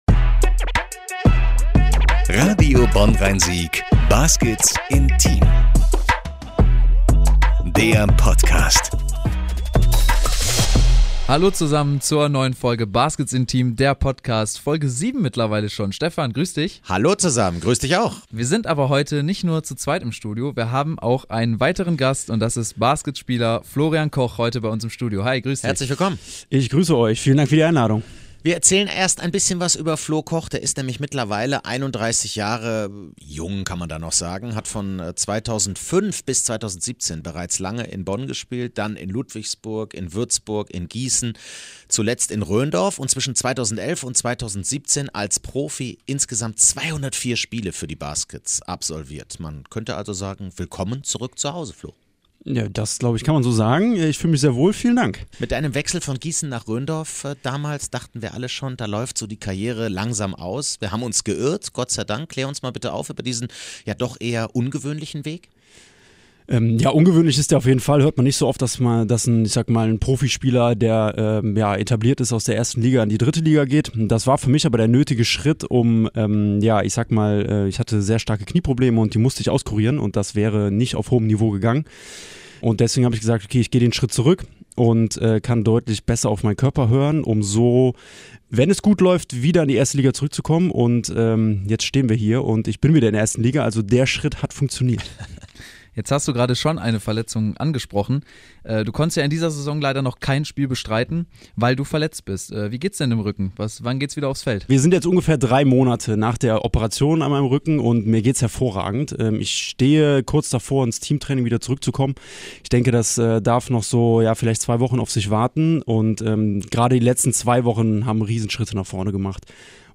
zu Gast im Studio.